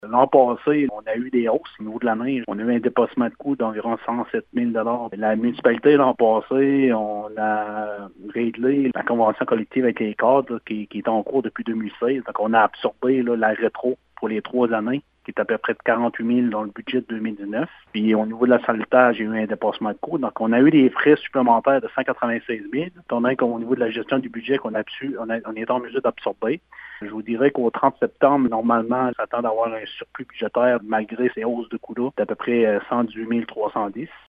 Les explications du maire de Grande-Rivière, Gino Cyr :